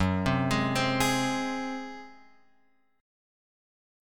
F#sus2b5 chord {2 3 x 1 1 4} chord